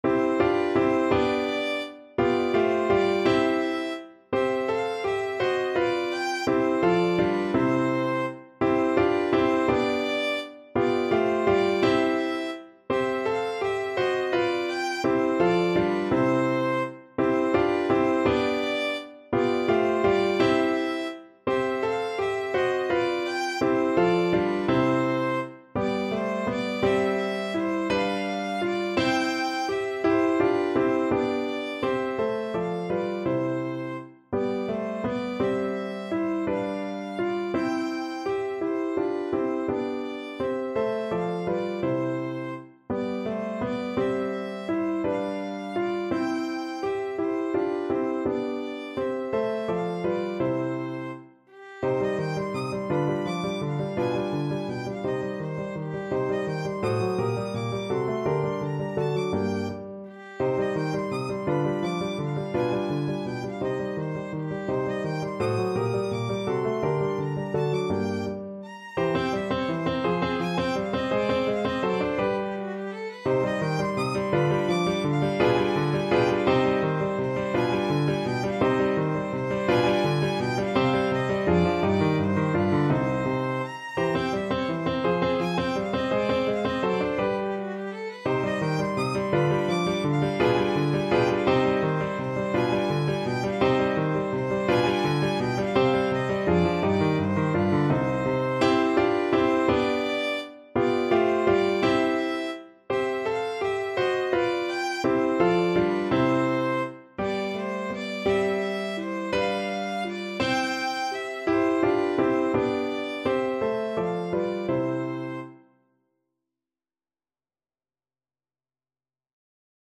Violin
Lustig (Happy) .=56
3/8 (View more 3/8 Music)
Classical (View more Classical Violin Music)
bagatelle_cminor_VLN.mp3